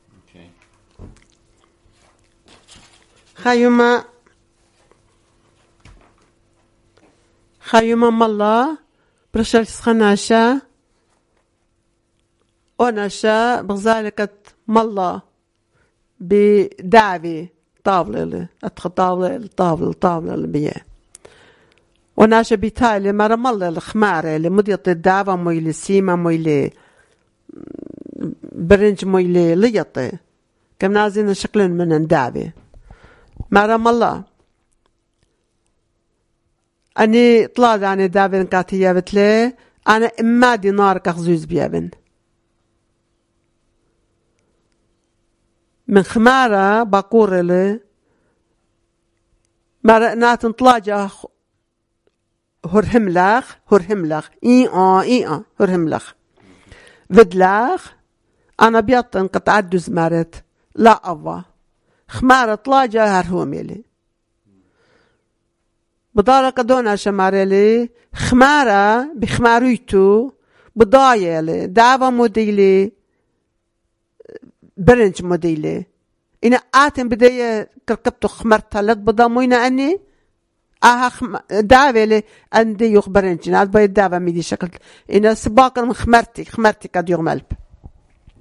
Urmi, Christian: A Donkey Knows Best